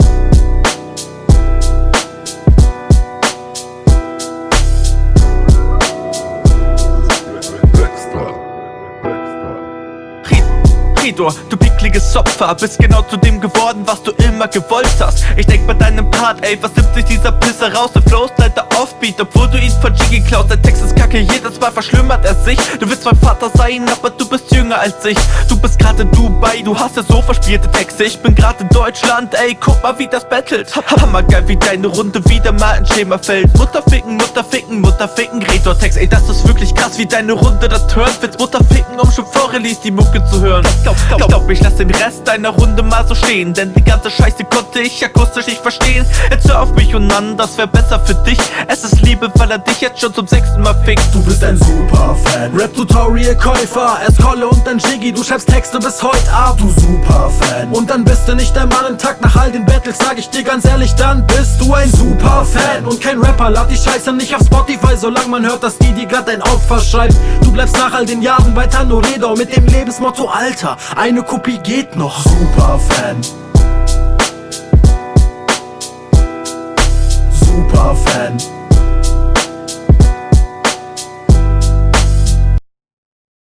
An sich bist du echt nicht schlecht im rappen.